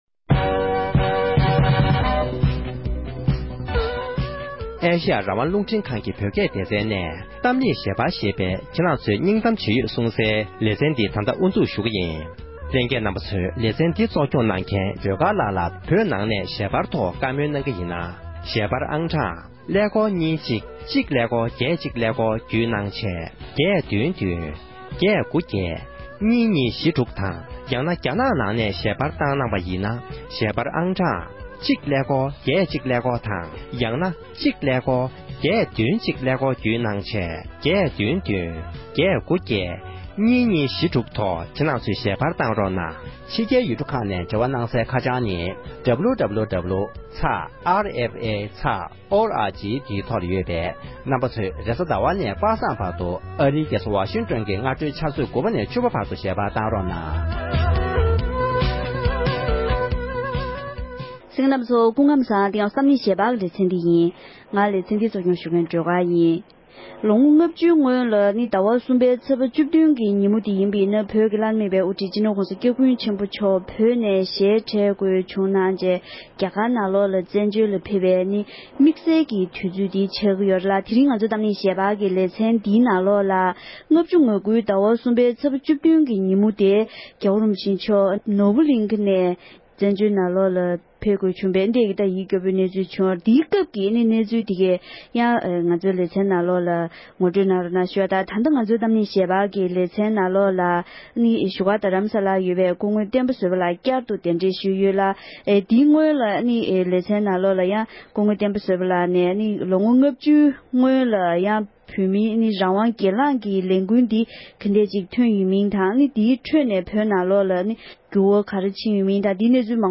ལོ་ངོ་ལྔ་བཅུའི་སྔོན་༸གོང་ས་མཆོག་བོད་ནས་གདན་འདྲེན་ཞུ་མཁན་གྱི་སྣ་ཁག་ཅིག་གི་ལྷན་བགྲོ་གླེང་ཞུས་པ།